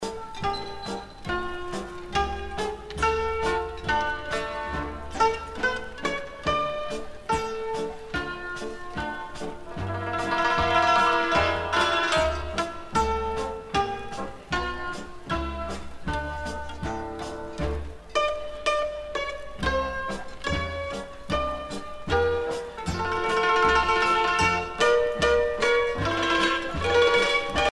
danse : hora (Roumanie)
Pièce musicale éditée